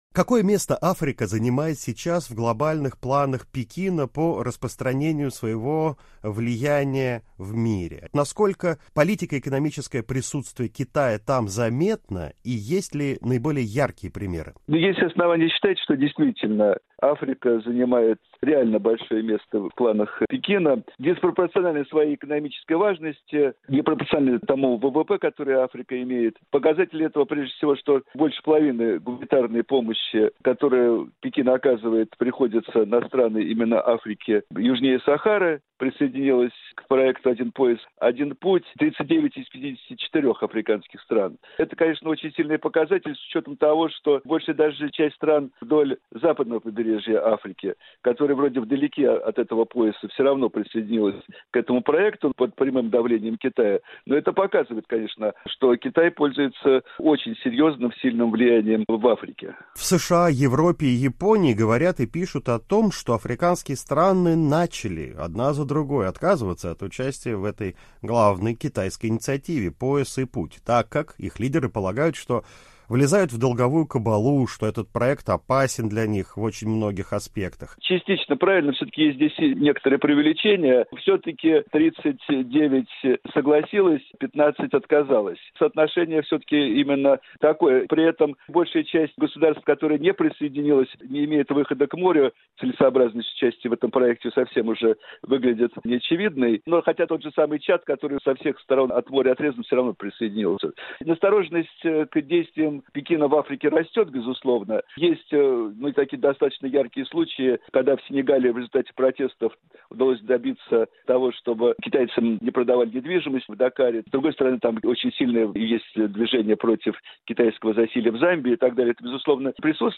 Мнение эксперта
Политолог